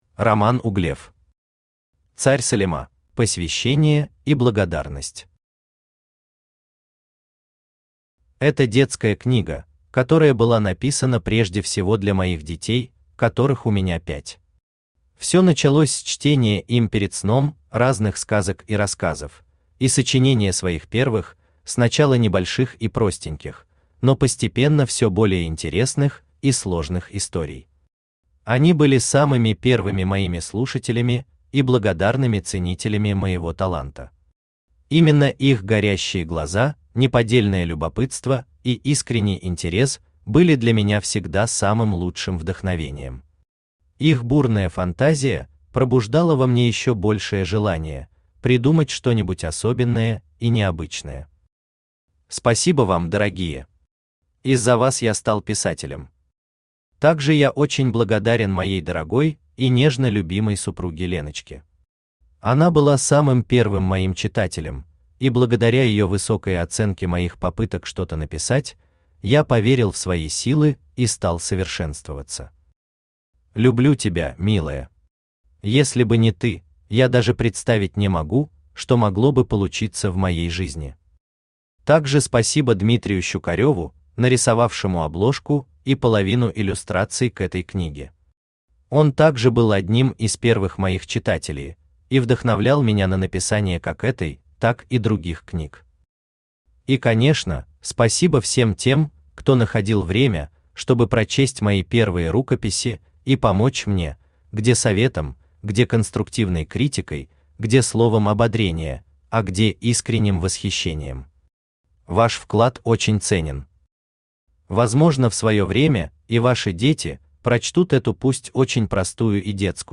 Аудиокнига Царь Салима | Библиотека аудиокниг
Aудиокнига Царь Салима Автор Роман Романович Углев Читает аудиокнигу Авточтец ЛитРес.